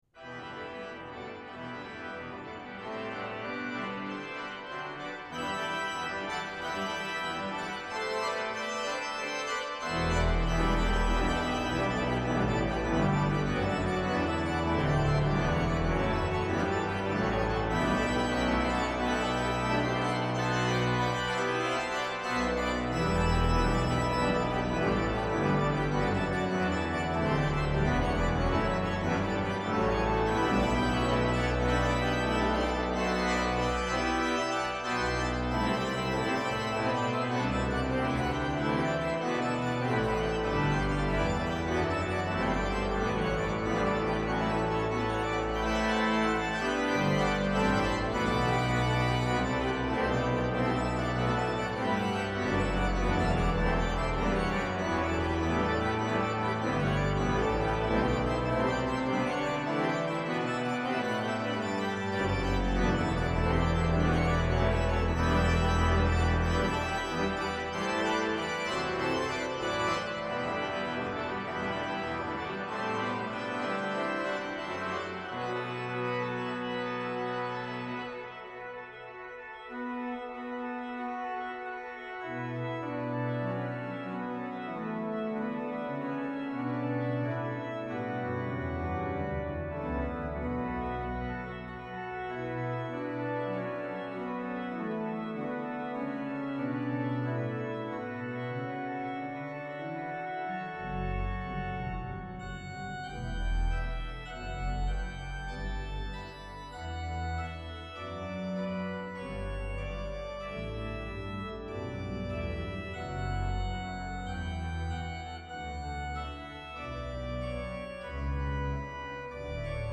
Voicing: Organ Duet